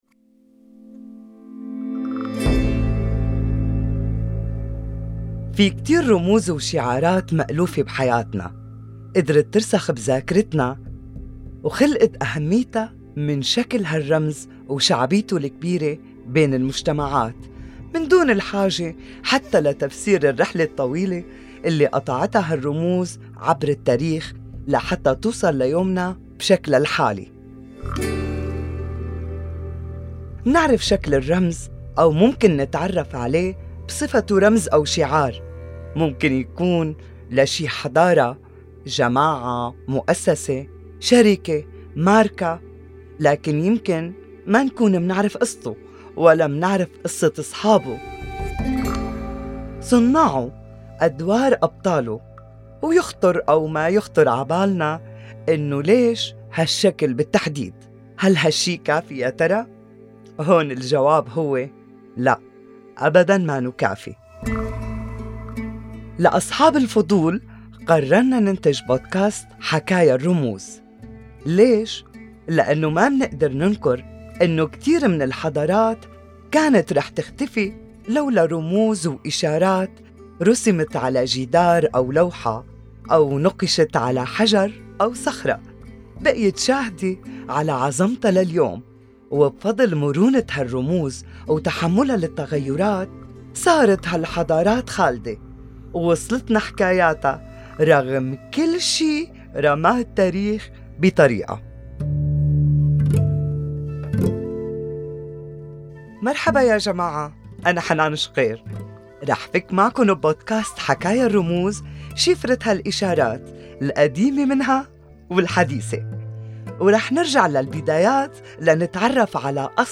حكايا الرموز - حلقة ترويجية